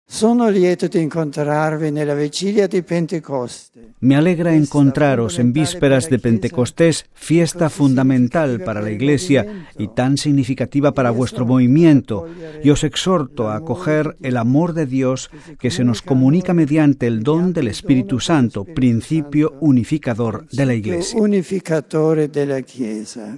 (RV).- Poco antes de mediodía en la plaza de San Pedro a los participantes en el encuentro organizado por el movimiento de Renovación en el Espíritu Santo.